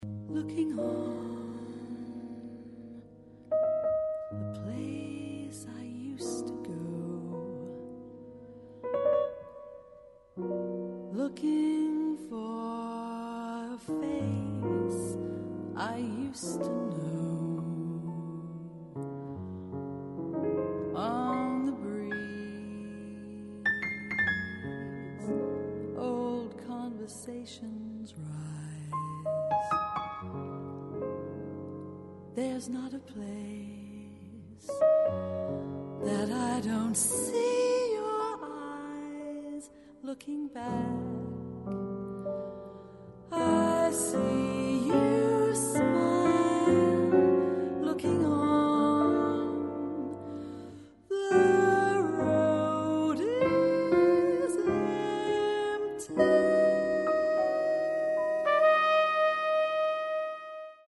Recorded April 1994 at Rainbow Studio, Oslo.